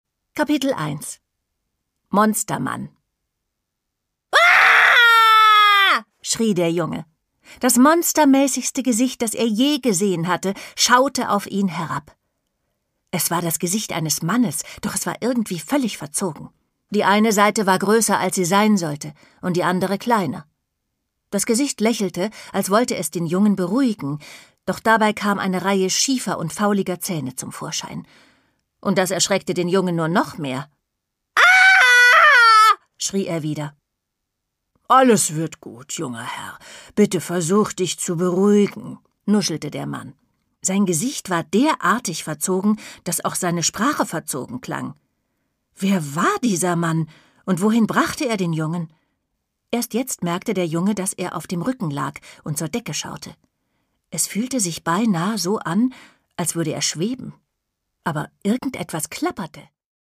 Produkttyp: Hörbuch-Download
Gelesen von: Andrea Sawatzki